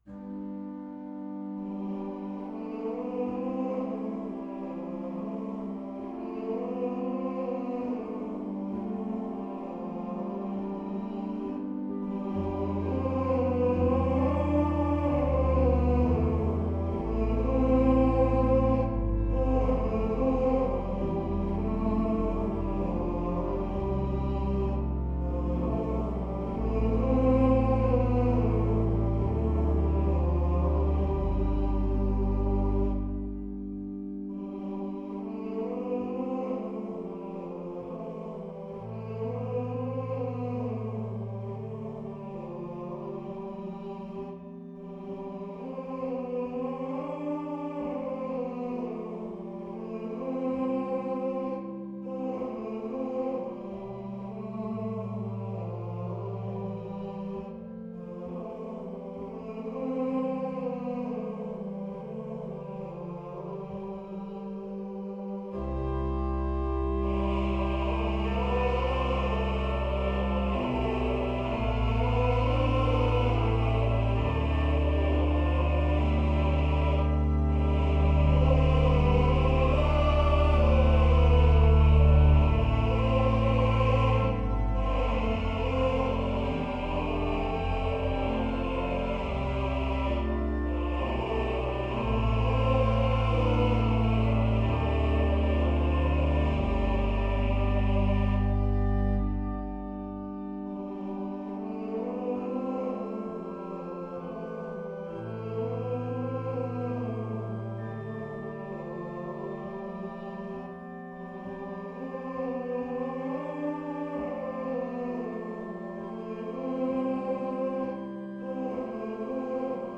Office hymn harmonisations
Since it has been the custom to sing Gregorian office hymns in the Choral Evensongs at the Dominicanenklooster (Dominican Convent) in Zwolle, I have composed an organ accompaniment each time.